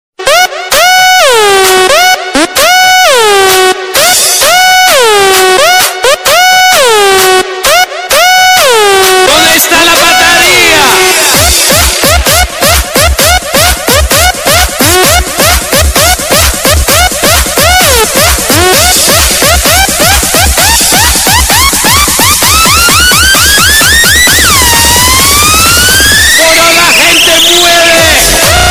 Categoria Sveglia